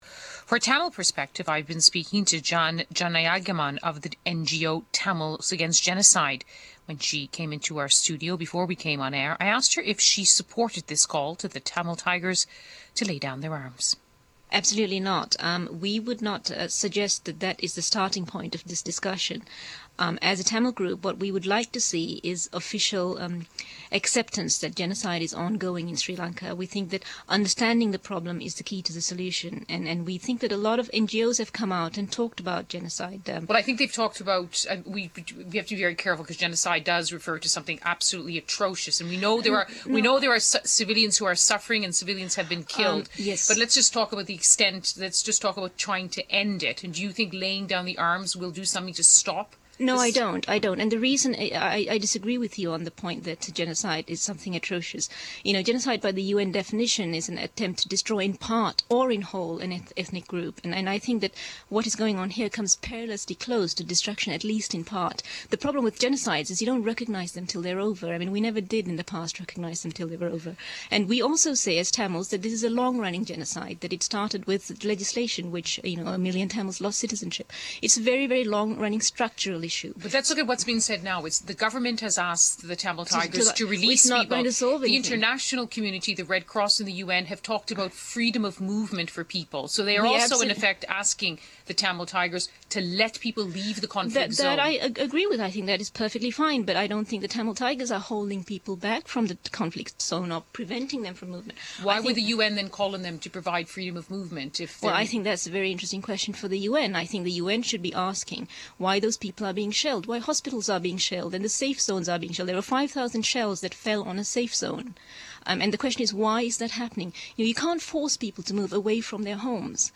PDF IconBBC Newshour interview with TAG